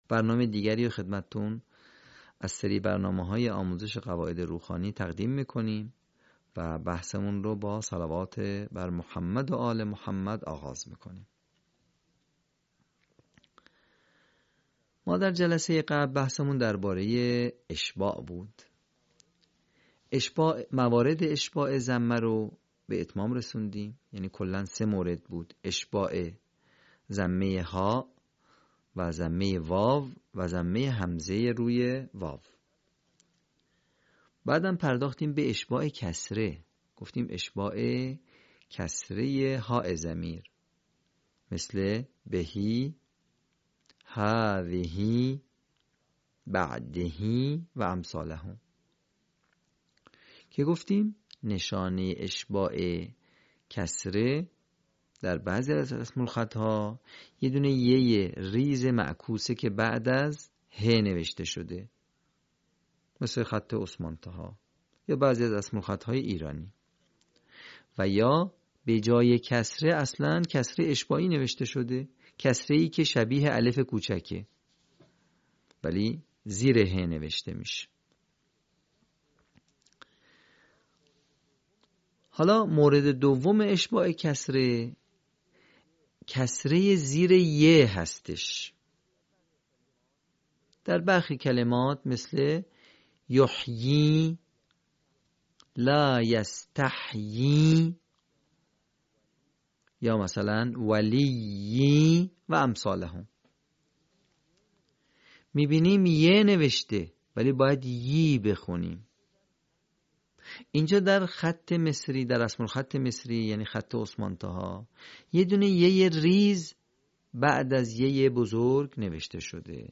صوت | آموزش روخوانی «اشباع کسره»